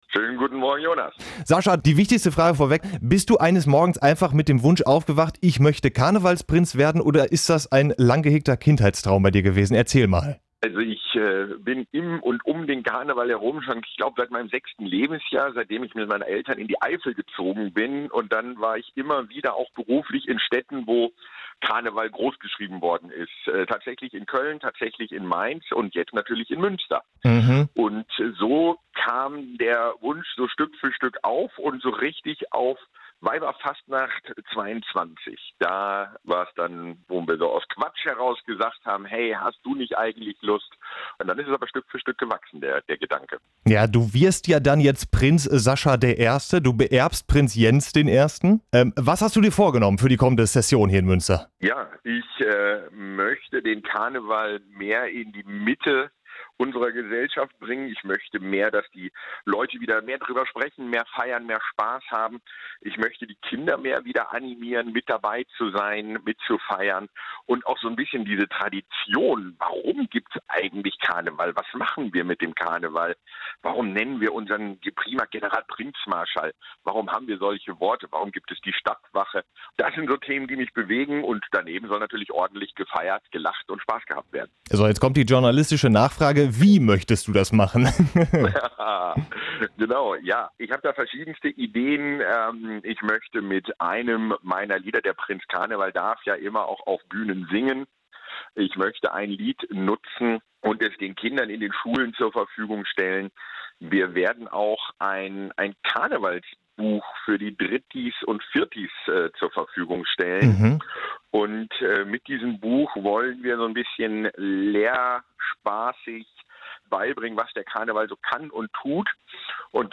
ANTENNE MÜNSTER Interview